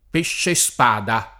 p%šše Sp#da] s. m.; pl. pesci spada — raro pescespada [id.]; pl. pescispada (non pescespada) — possibile, dove si stia parlando di pesci, un uso di spada masch. (sottinteso pesce): lo spada, gli spada — cfr. pesce